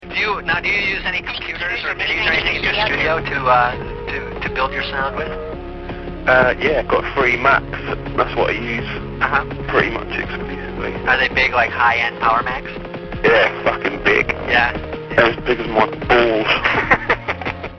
Aphex_Twin___1998_XX_XX_interview___They__re_As_Big_As_My_Balls.mp3